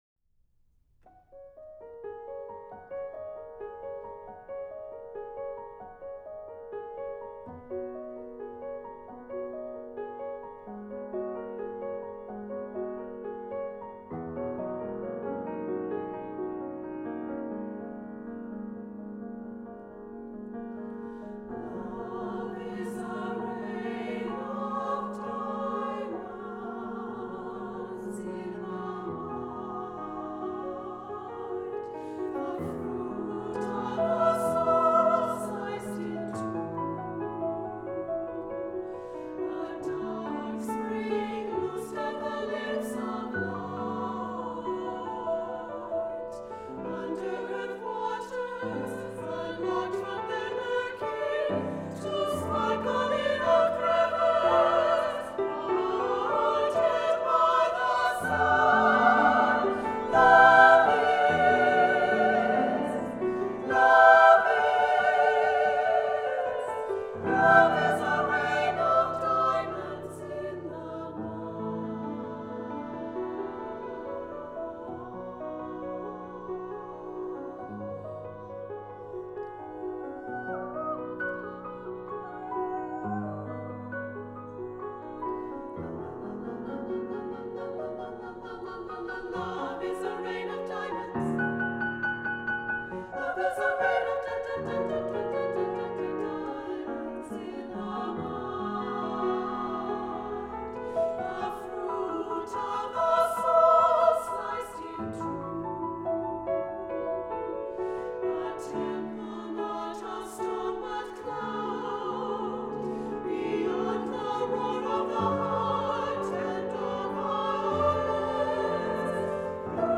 for SSAA Chorus and Piano (1993)